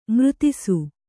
♪ mřtisu